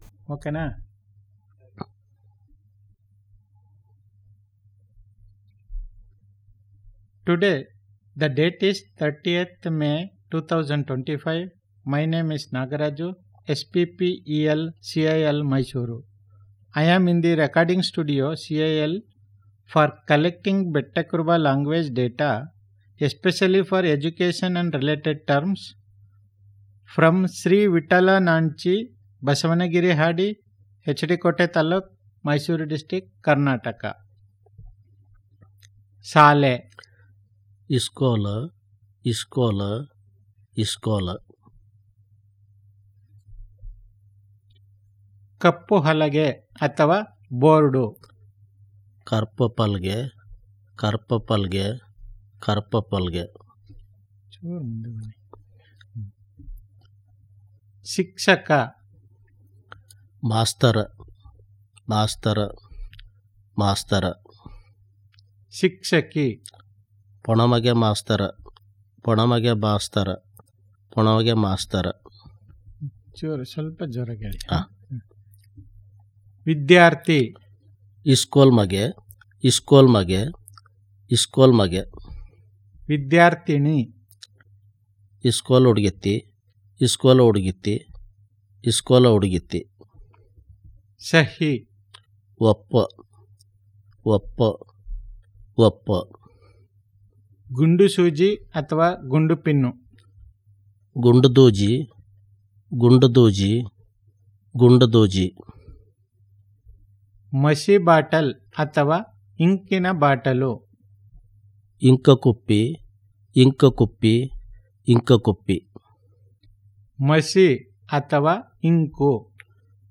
NotesThis is an elicitation of Vocabularies on the domain of Education related terms